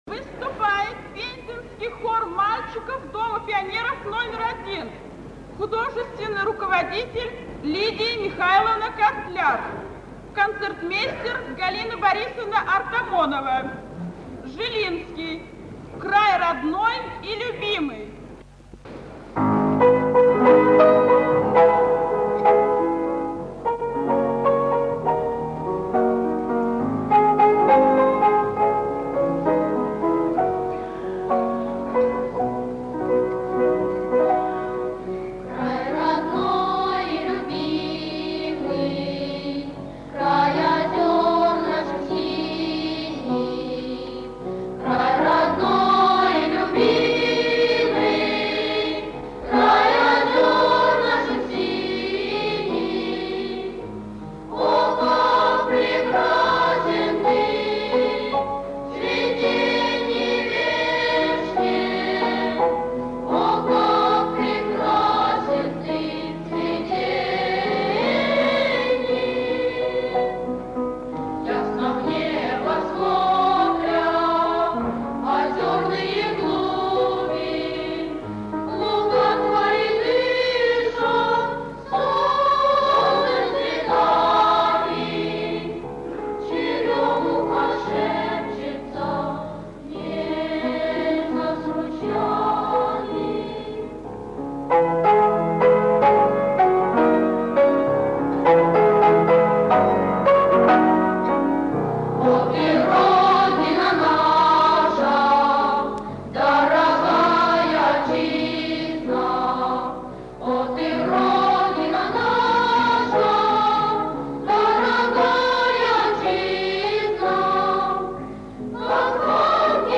Здесь песня даётся в сокращённом варианте.